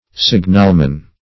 Signalman \Sig"nal*man\, n.; pl. -men.